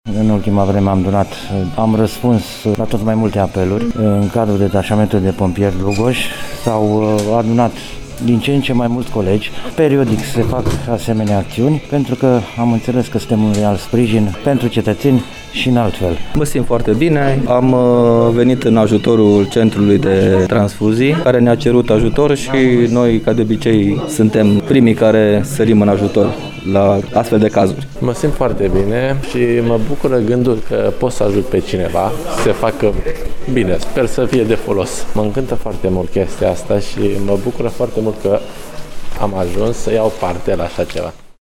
Pompierii spun că au vrut să ajute oameni şi altfel faţă de cum o fac în fiecare zi, când îi scot din flăcări sau de sub dărâmături.
Voxuri-pompieri.mp3